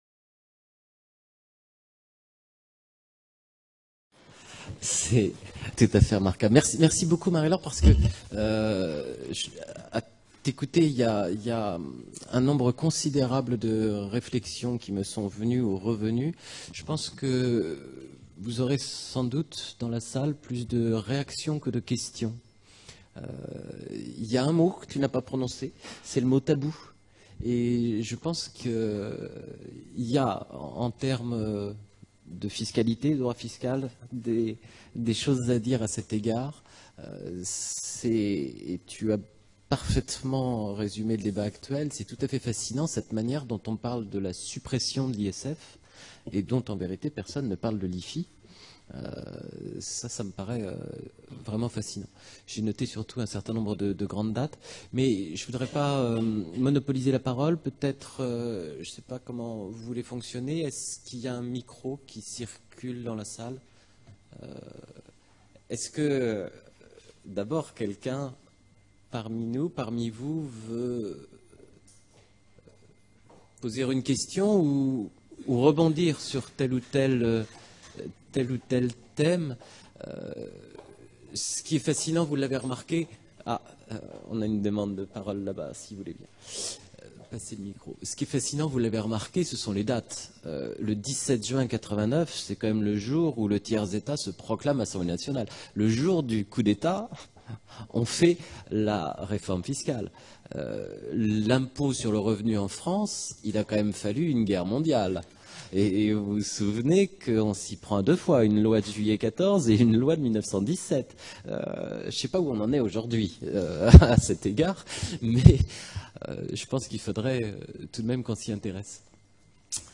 Discussion avec intervenants